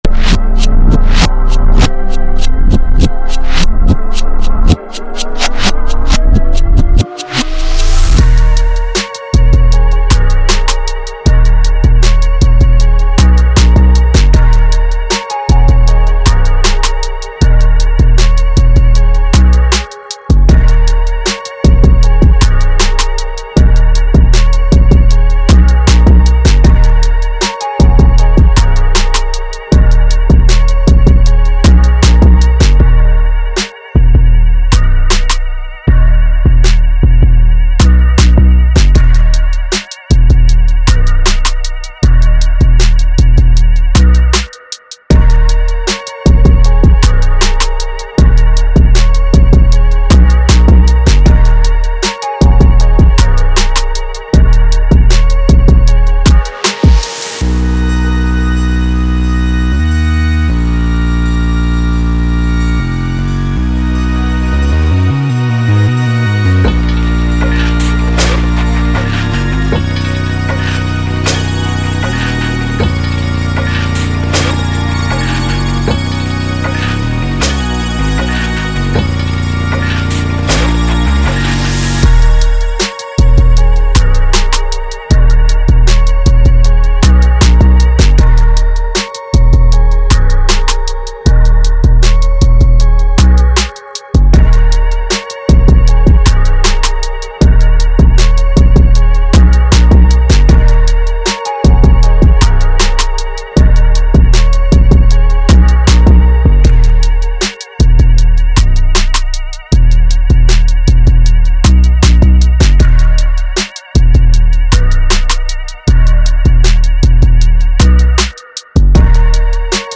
Dark/Psychedelic Trap